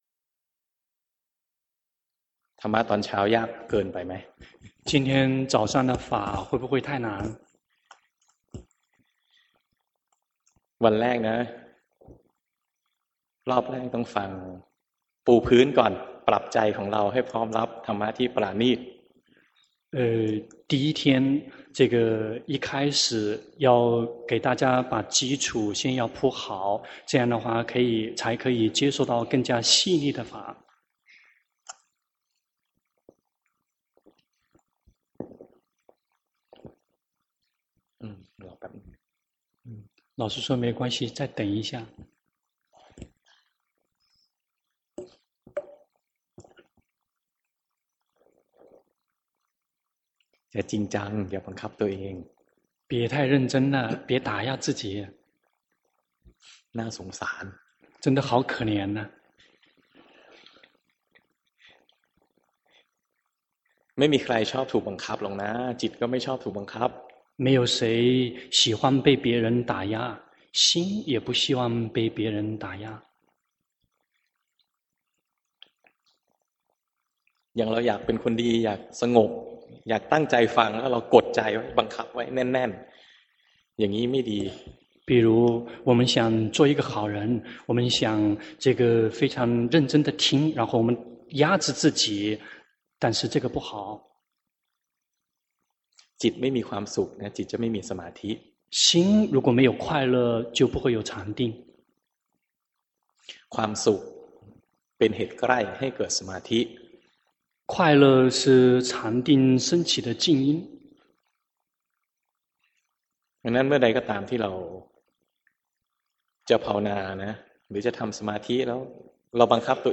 長篇法談｜法，毫無隱秘之處